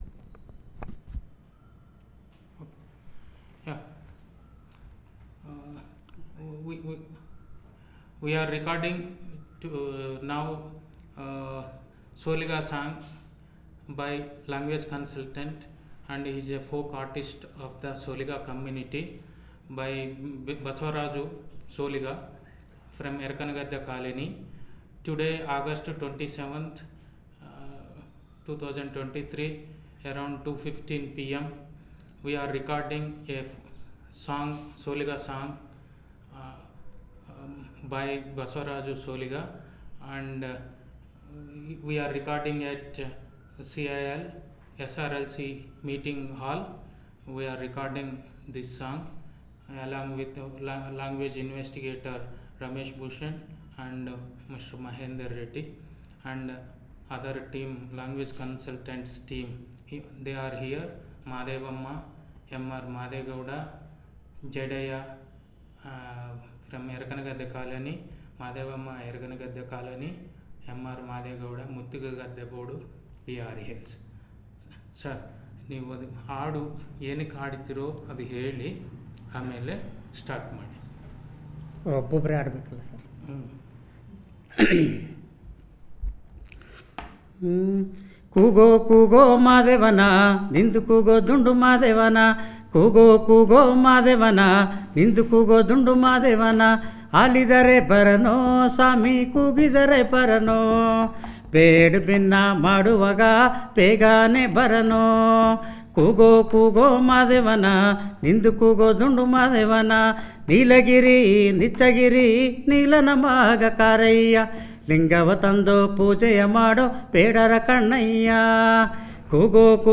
Elicitation of olaga song